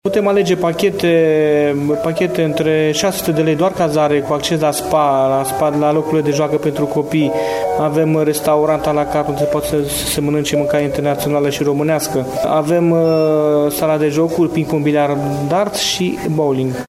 Manager de hotel